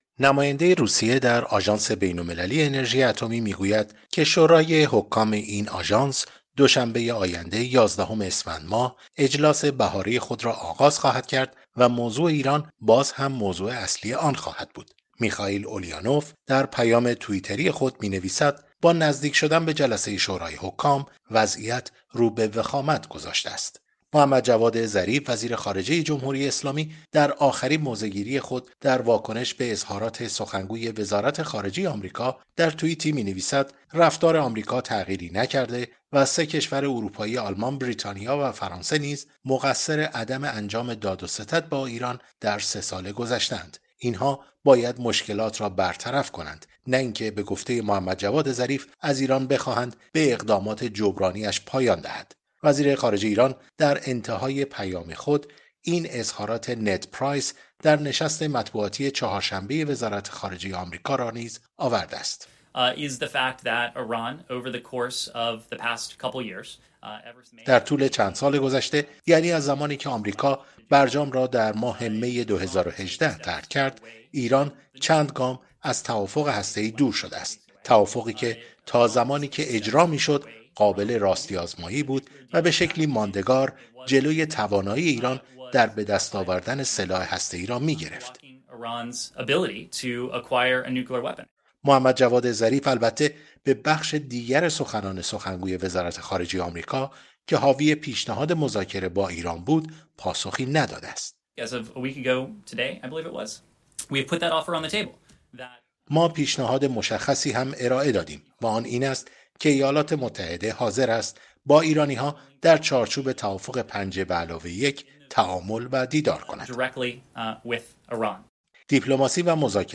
خبرها و گزارش‌ها